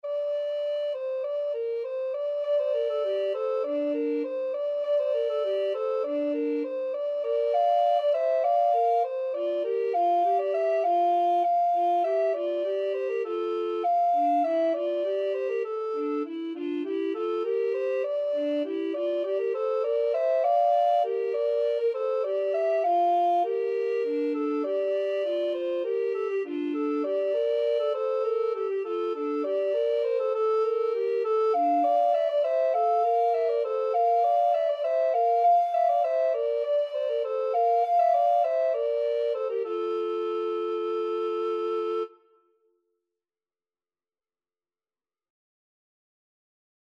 Tenor Recorder 1Tenor Recorder 2
Classical (View more Classical Recorder Duet Music)